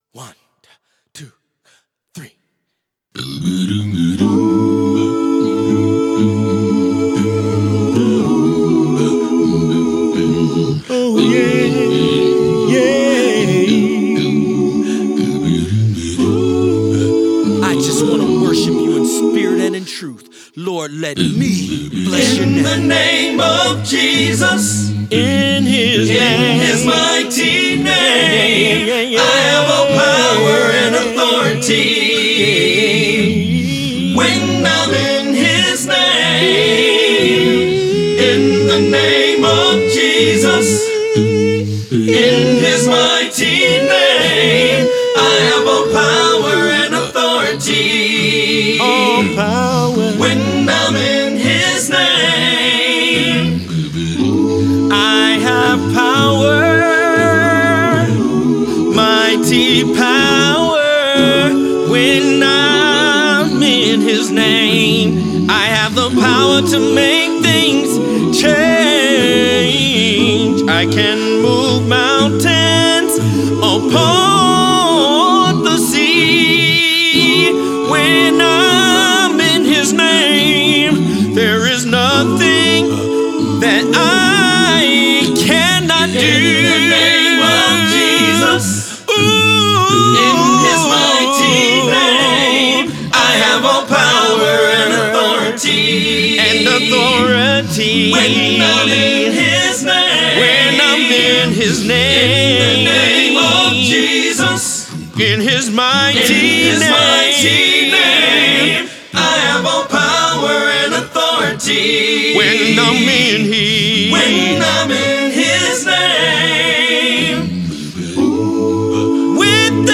soul-stirring acappella ride